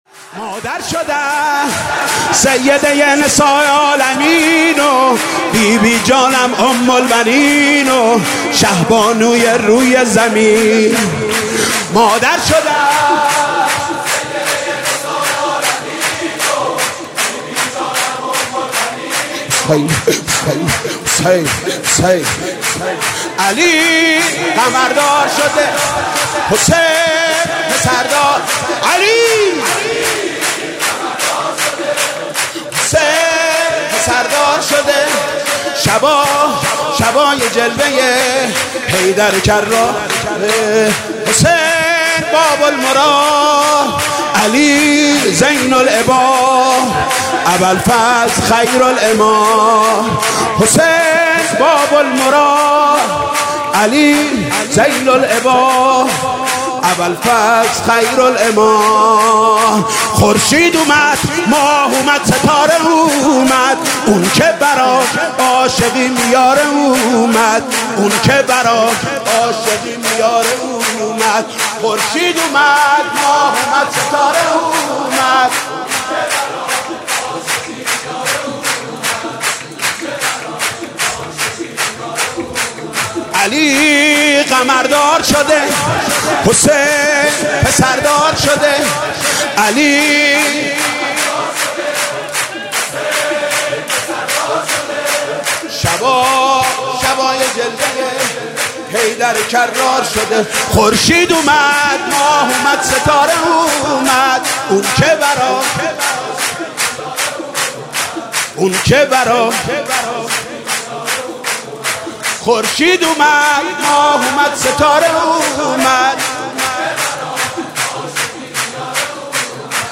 سرود: مادر شدن سیده نساء العالمین